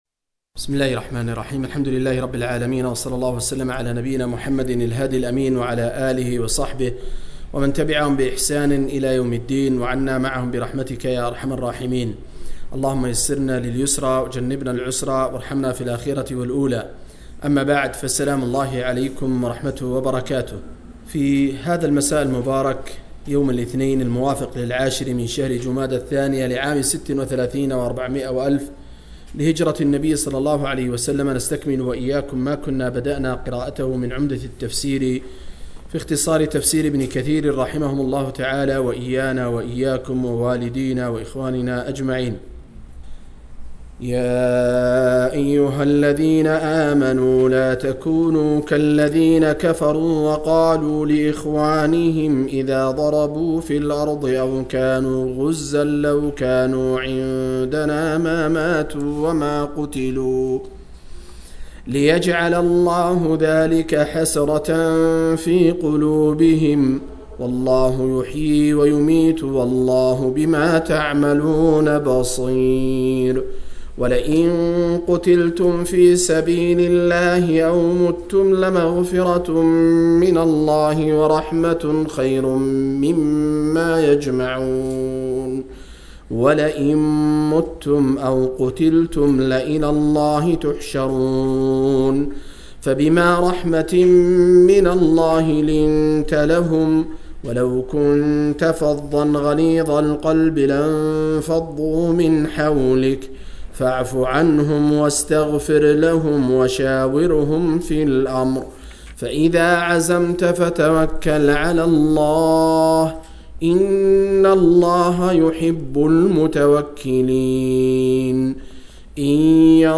076- عمدة التفسير عن الحافظ ابن كثير رحمه الله للعلامة أحمد شاكر رحمه الله – قراءة وتعليق –